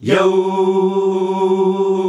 YOOOOH  G.wav